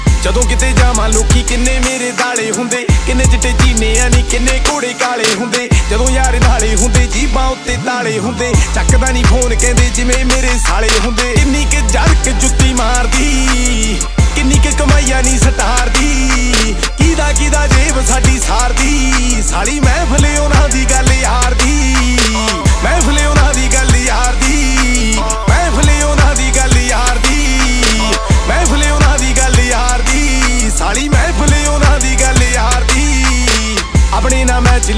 Pop Hip-Hop Rap
Жанр: Хип-Хоп / Рэп / Поп музыка